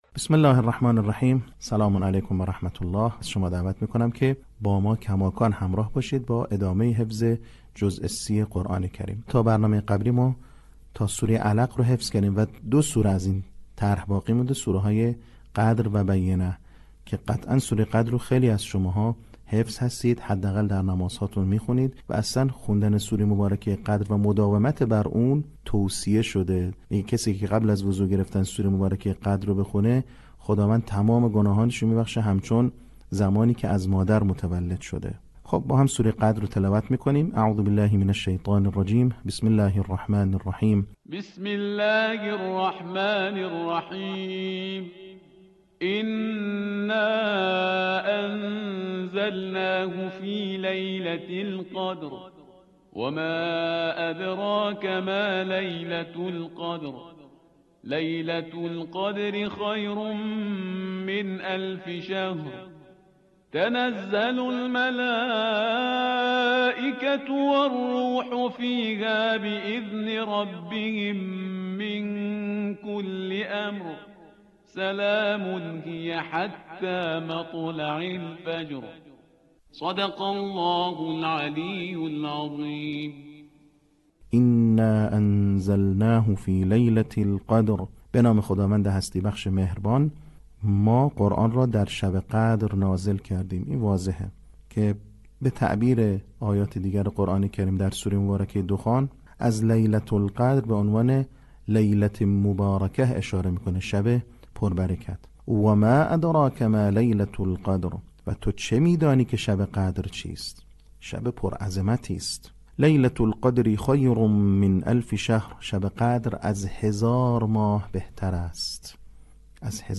صوت | آموزش حفظ سوره قدر